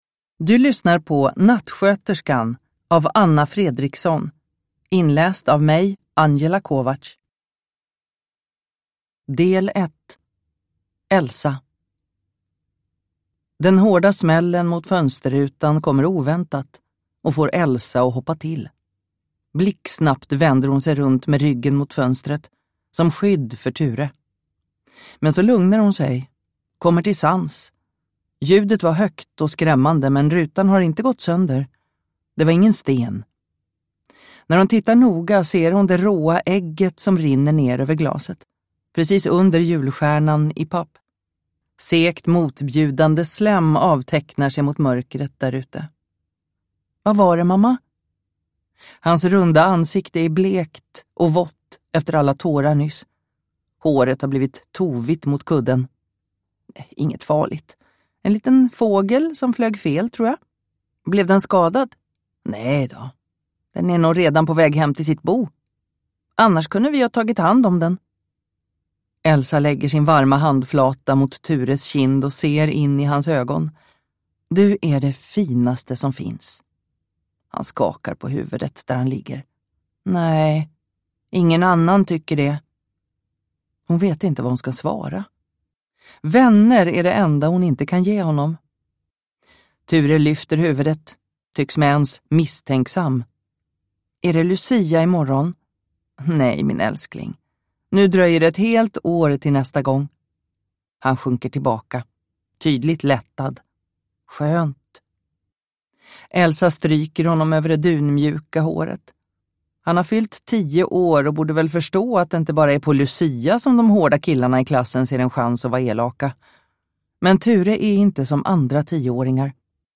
Nattsköterskan – Ljudbok